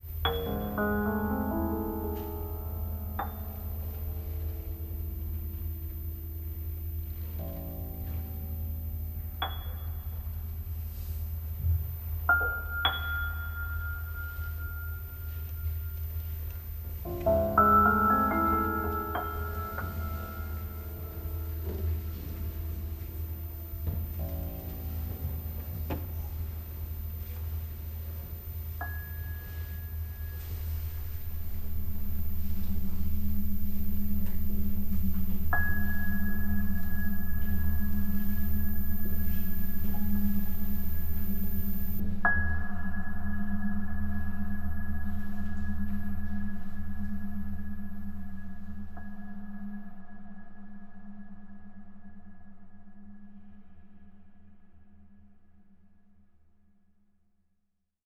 Piano-Abbozzo-1.mp3